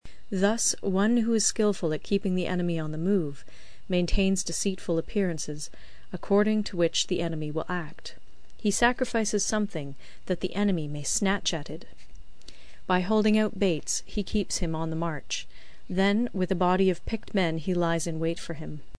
有声读物《孙子兵法》第30期:第五章 兵势(5) 听力文件下载—在线英语听力室